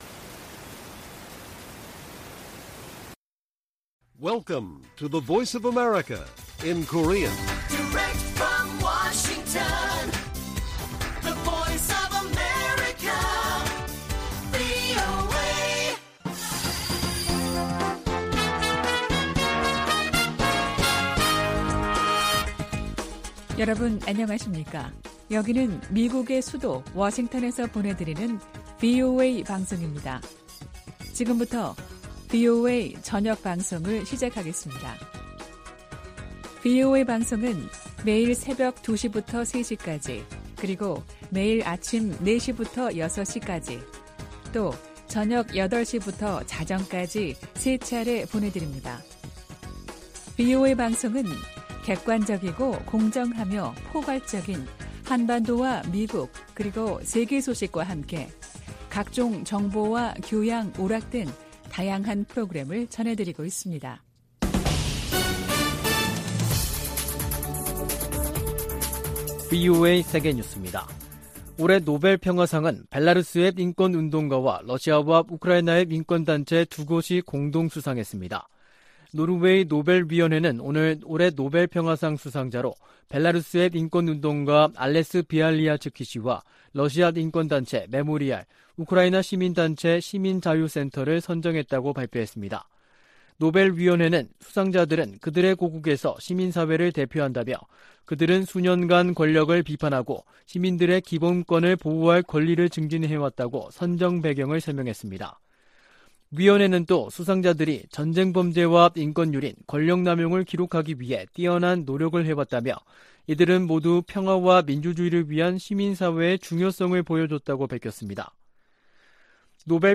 VOA 한국어 간판 뉴스 프로그램 '뉴스 투데이', 2022년 10월 7일 1부 방송입니다. 한국 정부는 북한이 7차 핵실험을 감행할 경우 9.19 남북 군사합의 파기를 검토할 수 있다고 밝혔습니다. 미국 정부는 북한의 탄도미사일 발사 등 도발에 대응해 제재를 포함한 여러 방안을 활용할 것이라고 밝혔습니다.